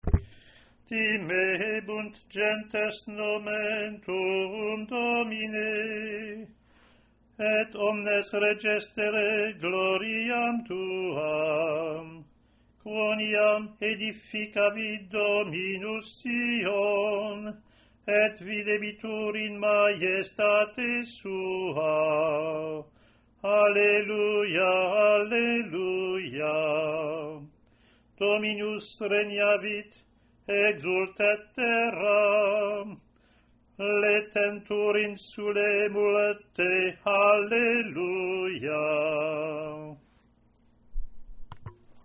Mode IV
USING PSALM TONE 4 WITH THE “ROSSINI PROPER”
3rd Sunday after Epiphany.